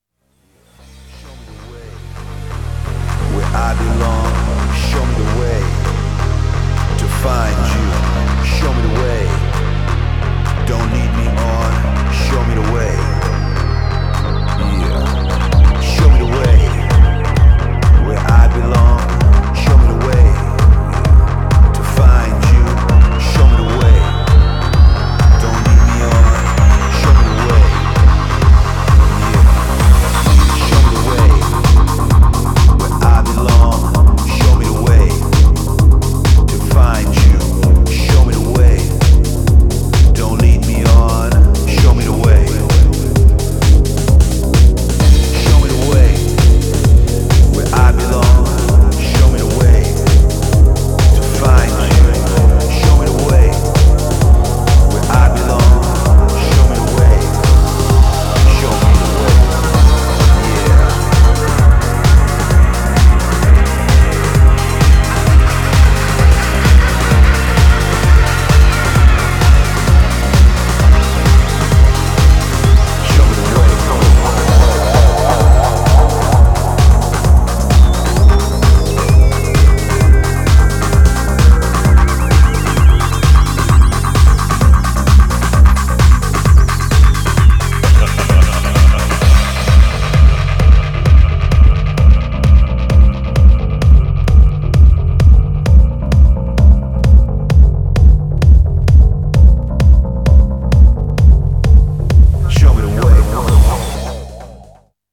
Styl: Progressive, House, Techno